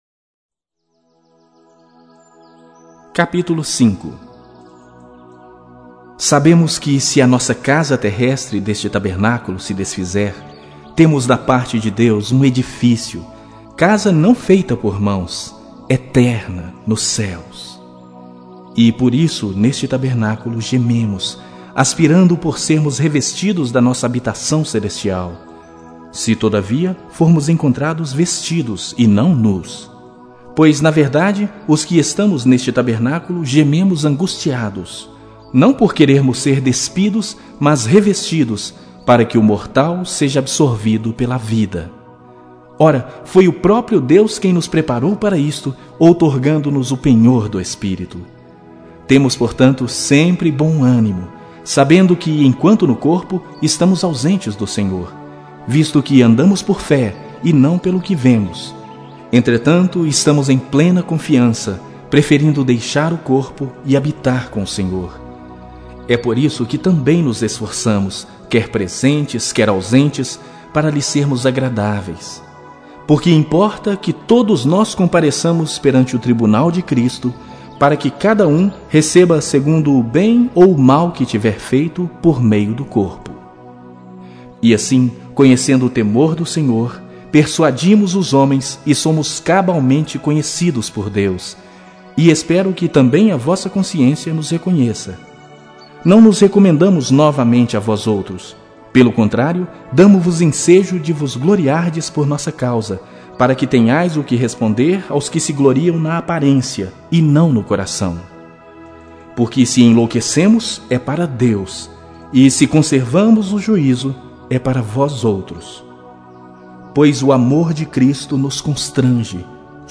Bíblia Sagrada Online Falada